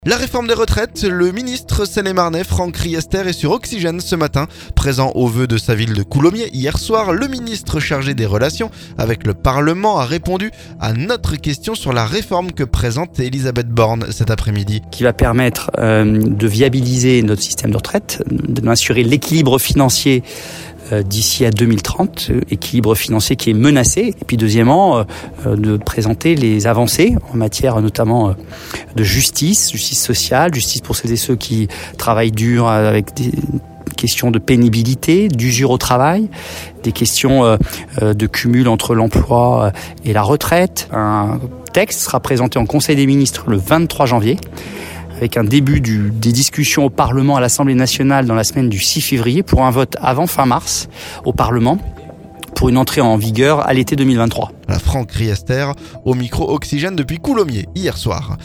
Réforme des retraites : le ministre seine-et-marnais Franck Riester sur Oxygène ce mardi. Présent aux vœux de sa ville de Coulommiers lundi soir, le ministre chargé des relations avec le Parlement a répondu à notre question sur la réforme que présente Elisabeth Borne ce mardi après-midi.